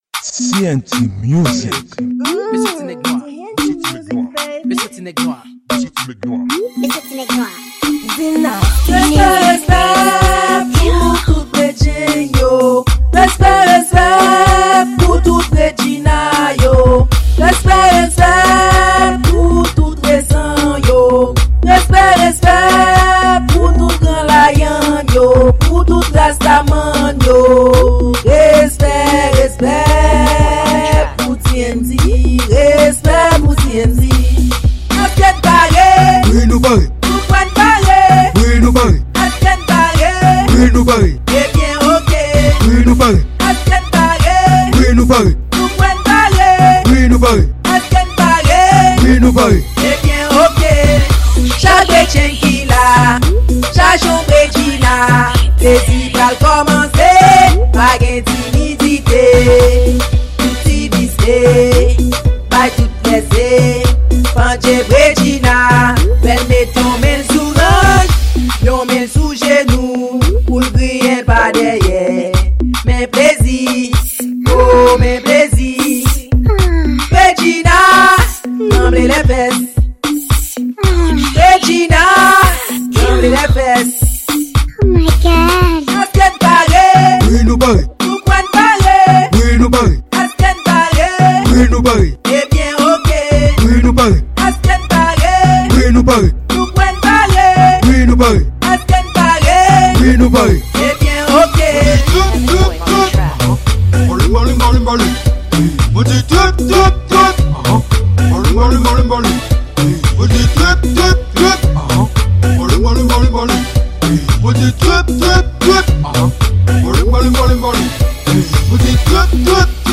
Genre: Afro.